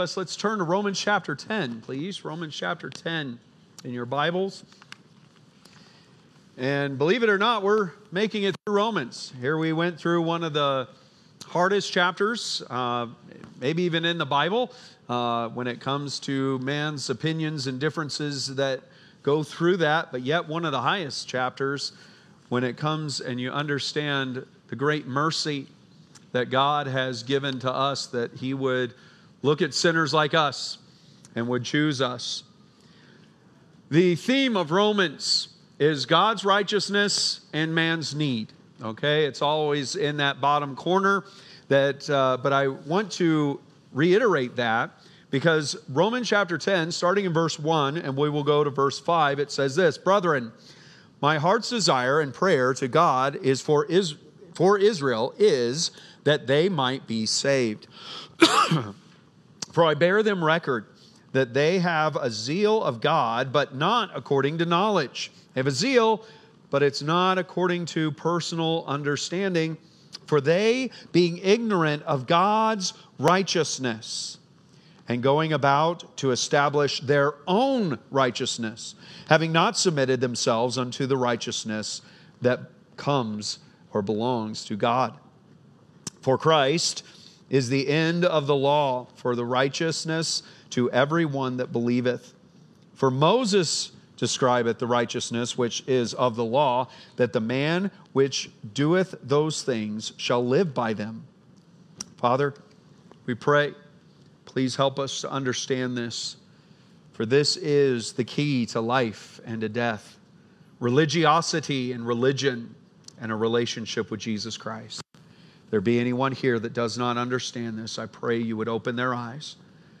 Date: February 2, 2025 (Sunday Morning)